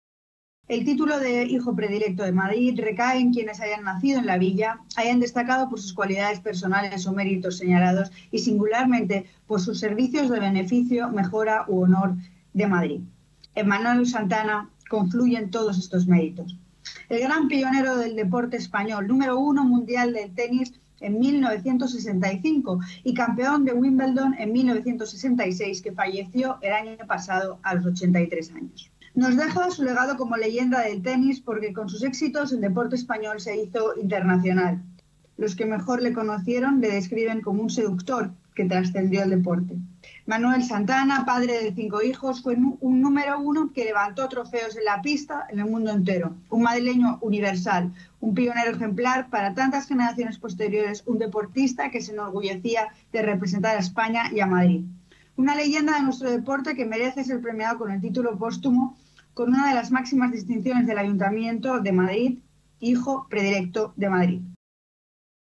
Nueva ventana:Declaraciones de la delegada de Cultura, Turismo y Deporte, Andrea Levy